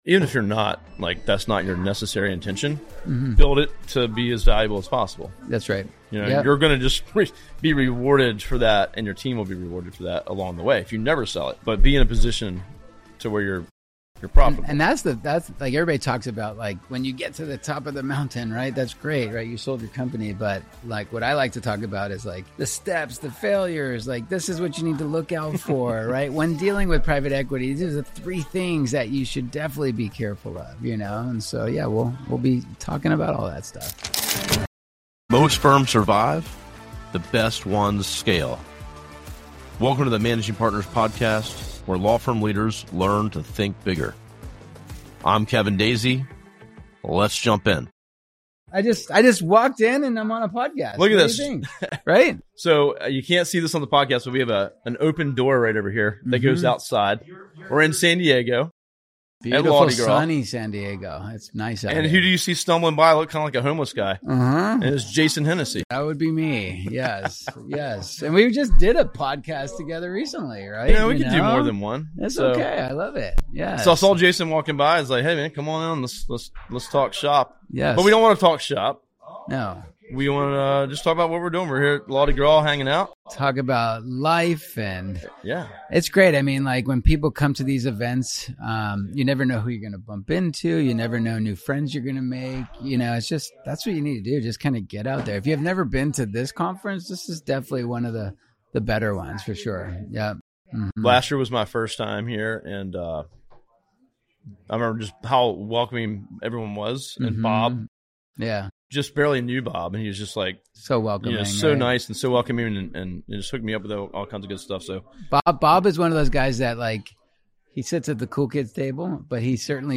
This energetic conversation highlights the value of networking, community, and continuous business development inside the legal industry.